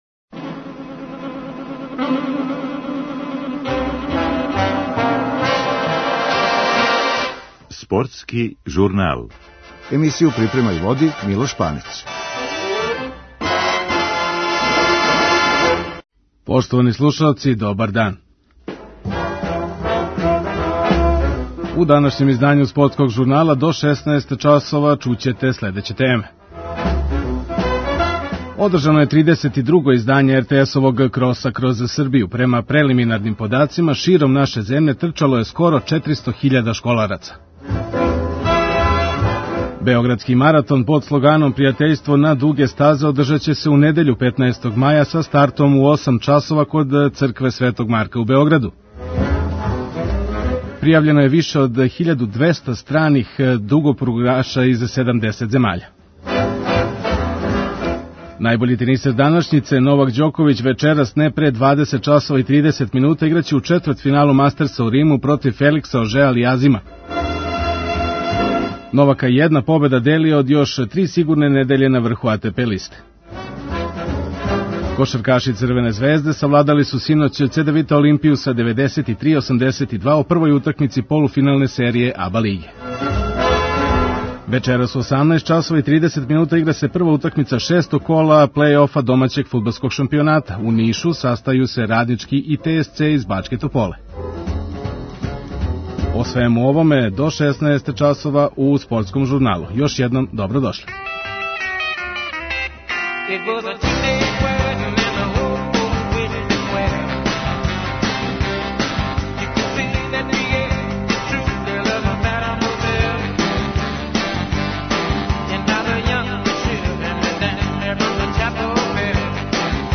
Чућемо и прве утиске министра просвете, али и такмичаре који су трчали широм Србије.